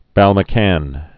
(bălmə-kăn, -kän)